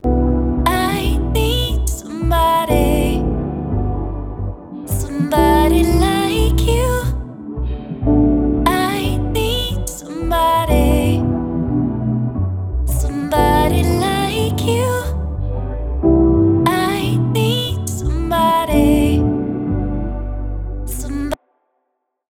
Before Pitch Shifter
no-pitchshift.mp3